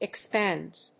/ɪˈkspændz(米国英語)/